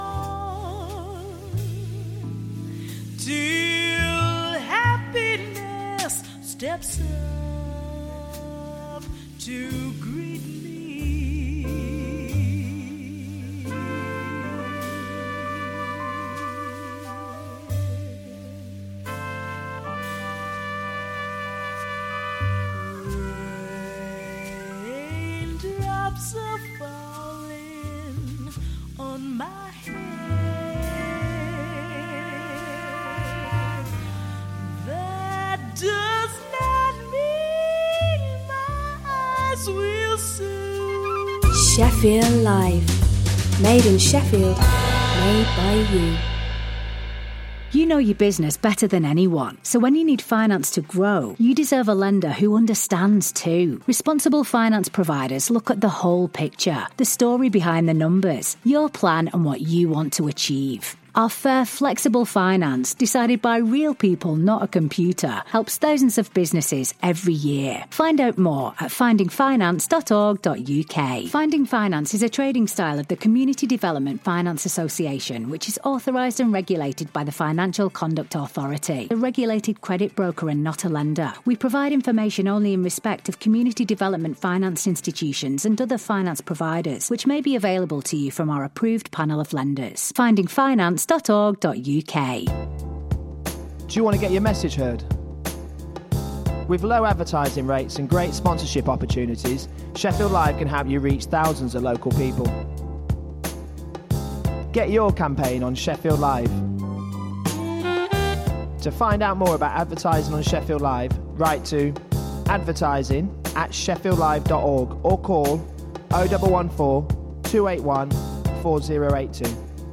Adal Voice of Eritreans is a weekly radio magazine programme for the global Eritrean community.